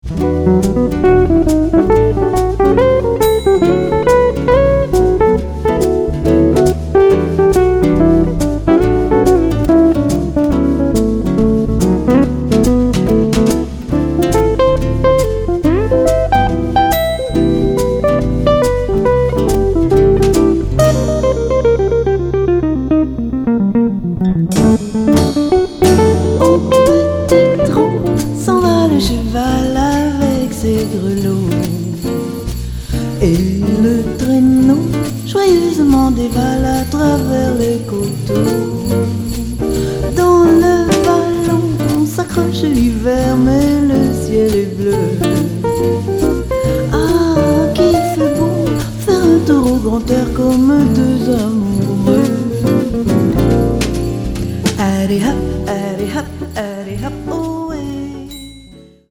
guitare cordes de nylon et percussions
contrebasse
guitare électrique